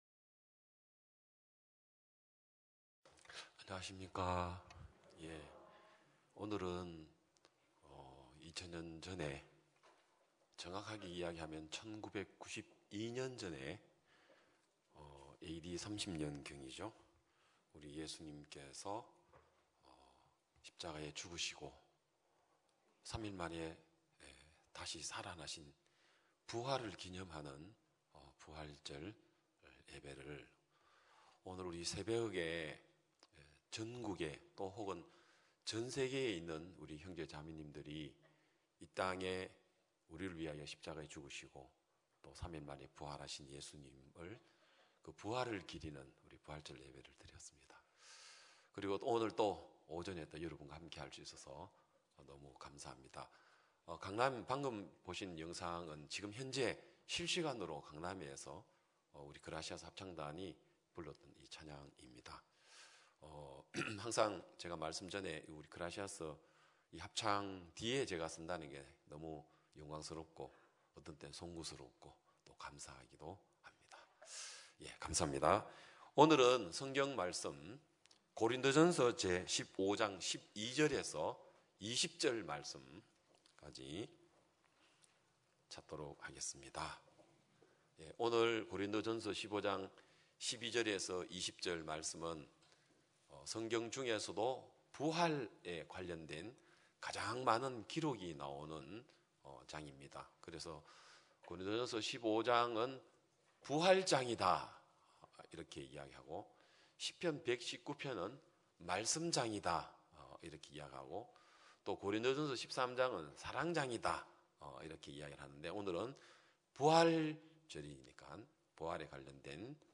2022년 4월 17일 기쁜소식양천교회 주일오전예배
성도들이 모두 교회에 모여 말씀을 듣는 주일 예배의 설교는, 한 주간 우리 마음을 채웠던 생각을 내려두고 하나님의 말씀으로 가득 채우는 시간입니다.